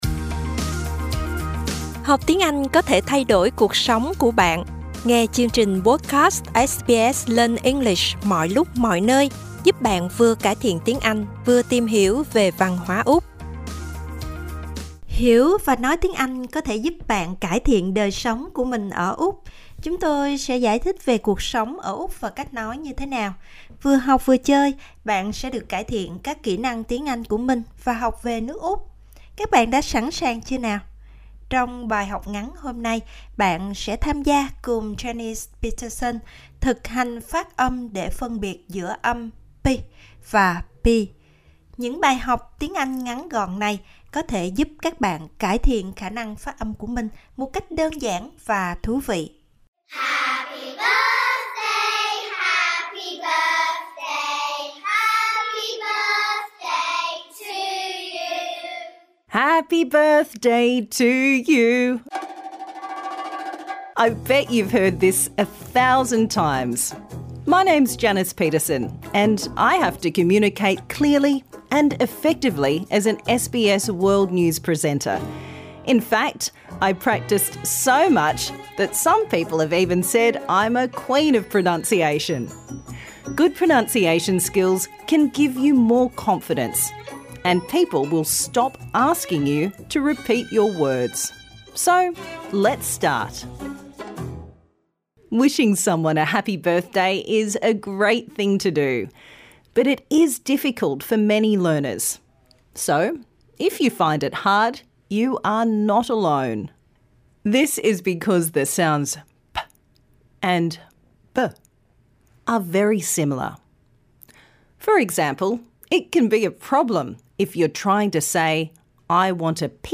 Minimal Pairs: /p/ pin pill pack pig pie pet /b/ bin bill back big buy bet SBS Learn English will help you speak, understand and connect in Australia.